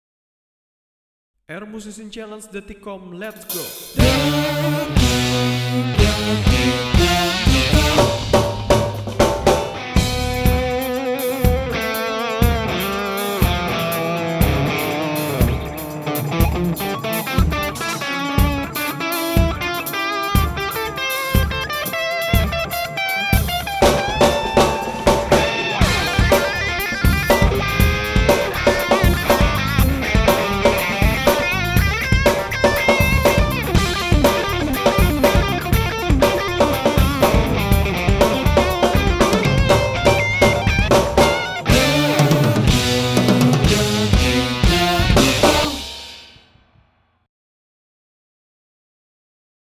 1. Kamu harus download lagu pengiring terlebih dulu.
- Gitar klik